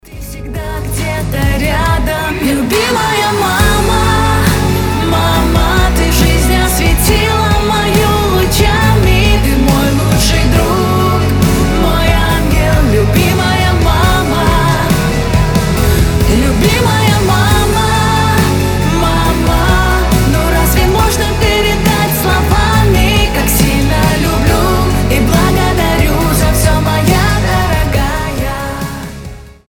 Нежные рингтоны